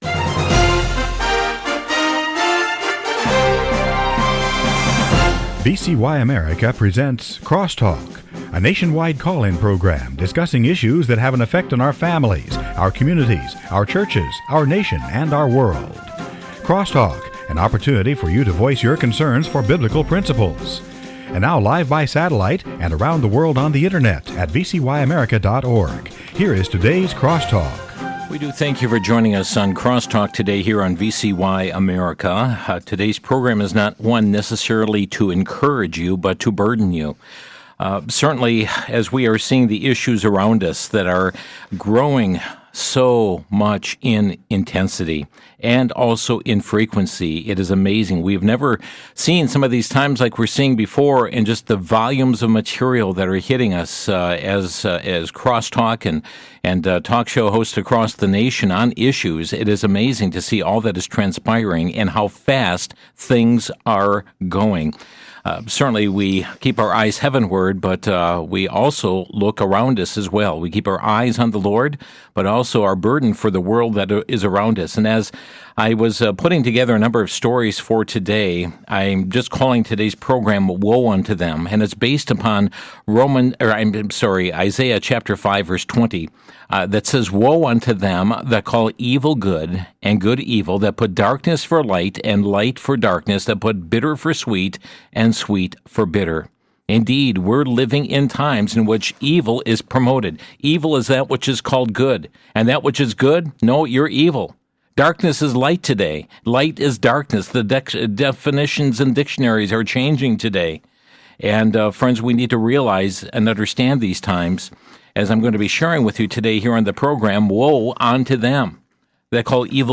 Woe Unto Them | SermonAudio Broadcaster is Live View the Live Stream Share this sermon Disabled by adblocker Copy URL Copied!